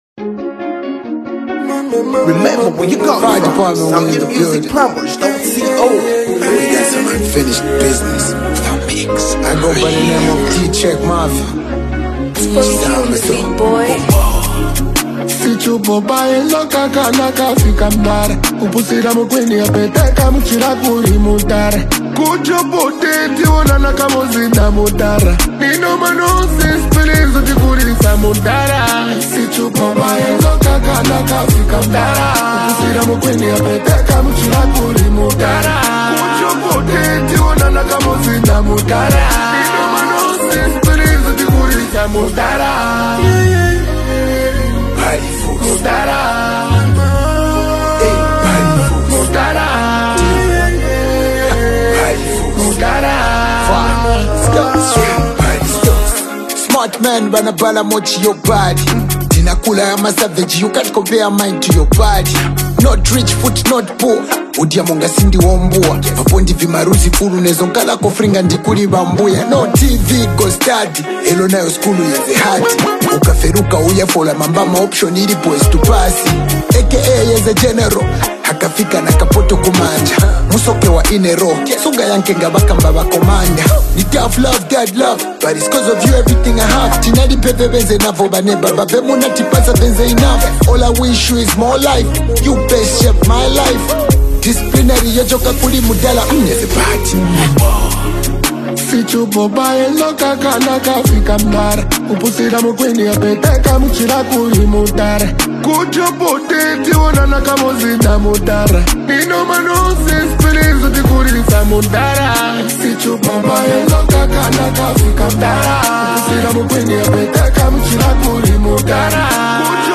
Genre: African Music